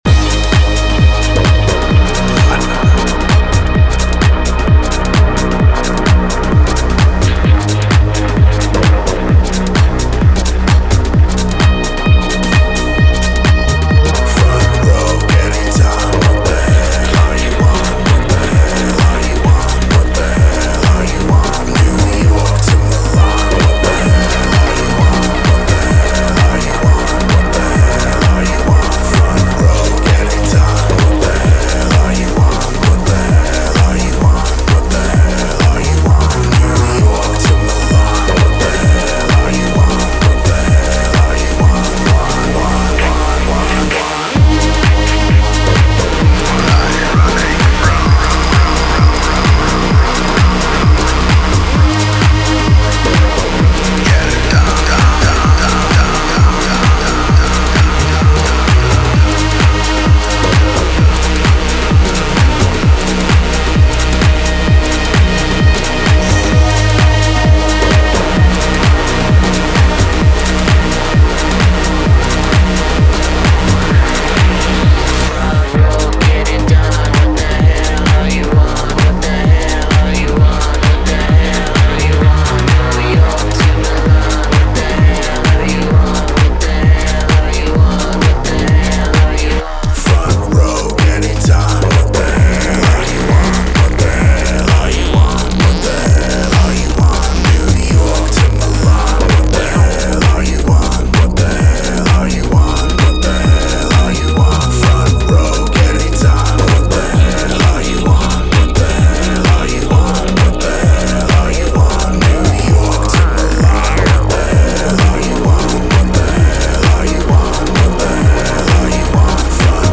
a collection of 4 tracks fine-tuned for the chaotic club.
takes the foreground approach